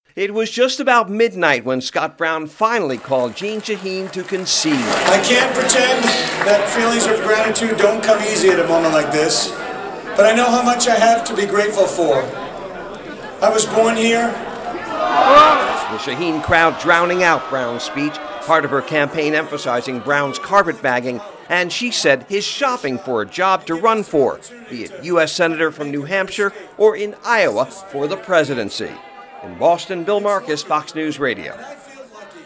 Shaheen Victory Speech
U.S. Senator Jeanne Shaheen (D-NH) thanks supporters shortly after midnight at the Puritan Conference Center in Manchester.